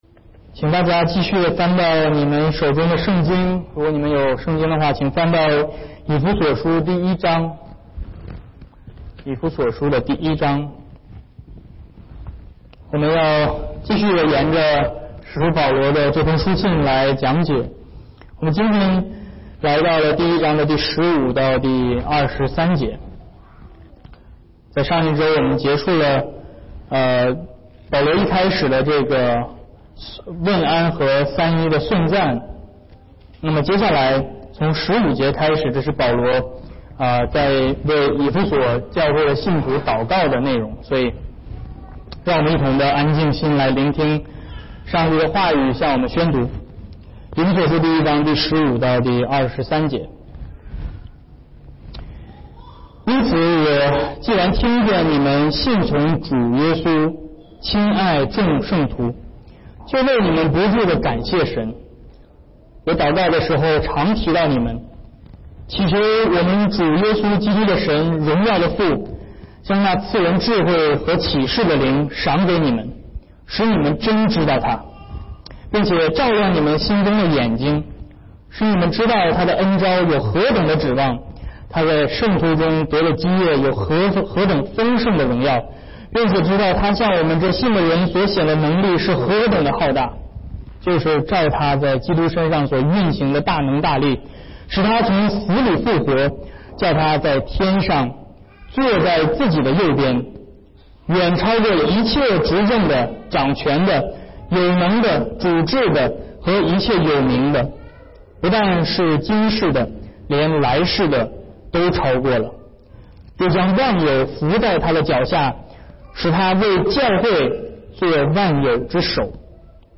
Ephesians1:15-23 Service Type: Sermons Download Files Notes « 以弗所书